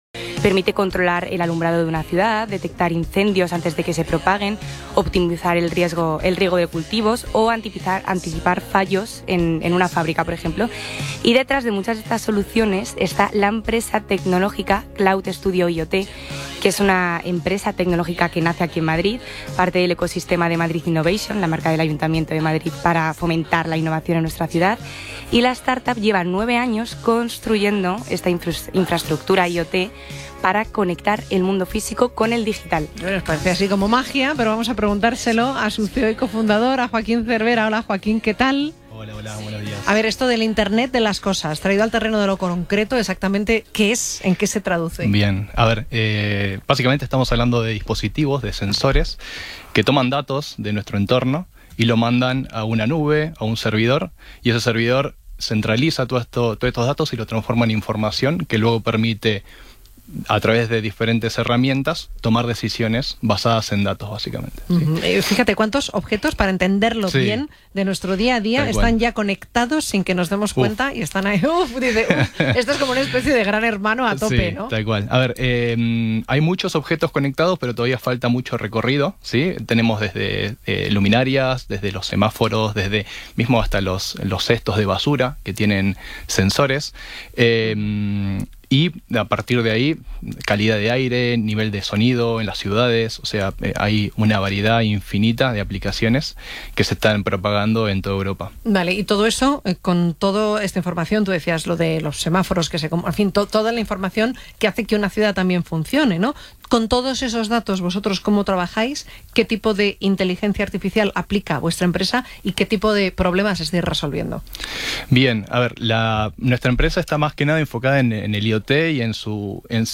Cloud-Studio-IoT-Cadena-Ser.mp3